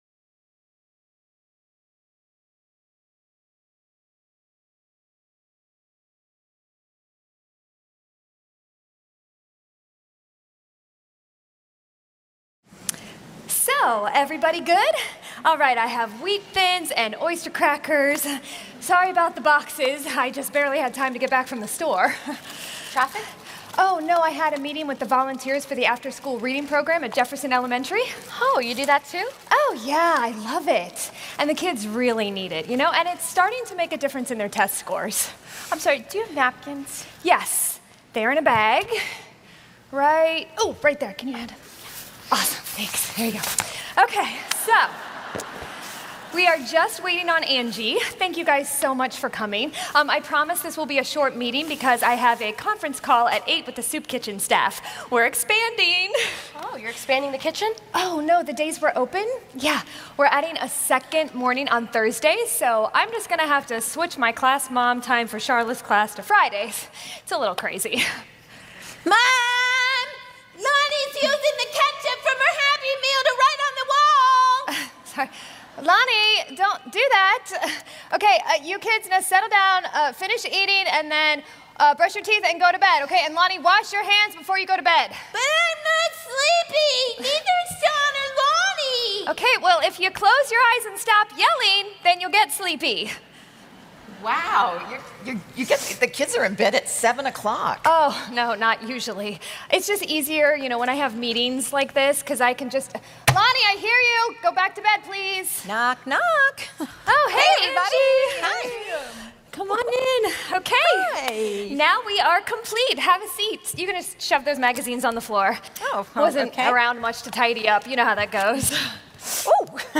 Message (Drama) 10: The VBS Meeting